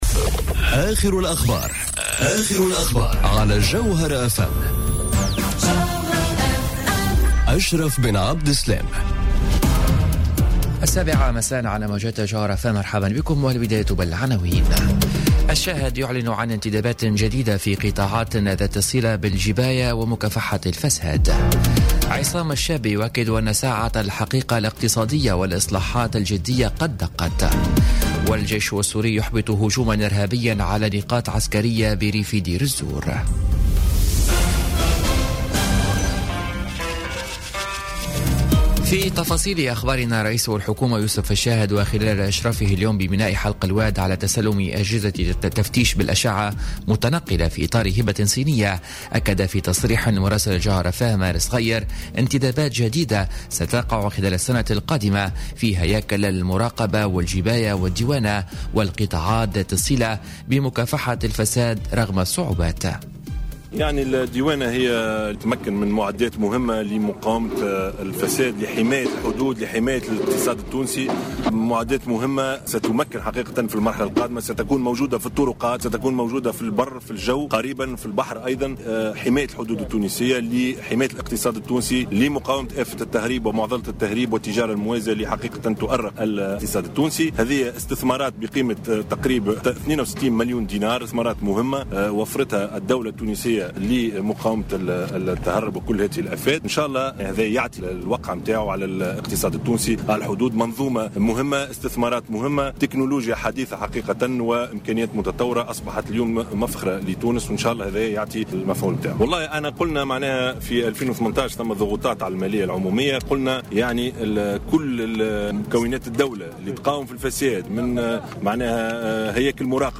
نشرة أخبار السابعة مساء ليوم السبت 30 سبتمبر 2017